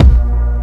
Obie Kick.wav